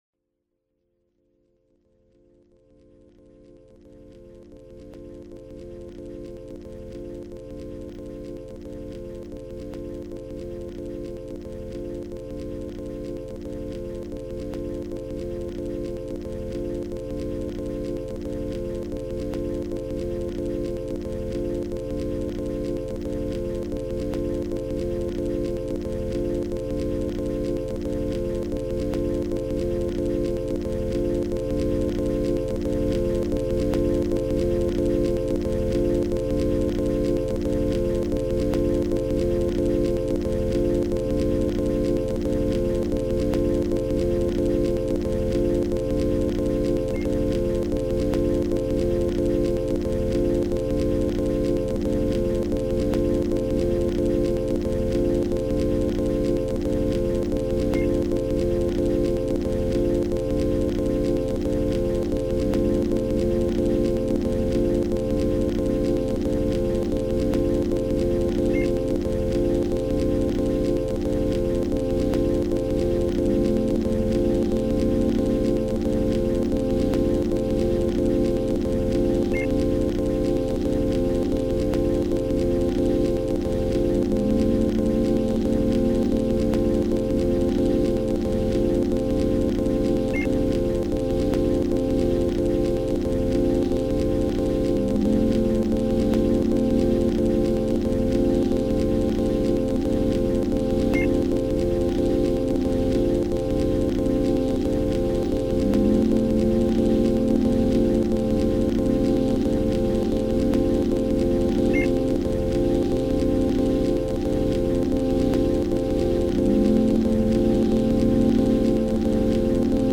Exceptionally soft and clear substance.
ambient electronic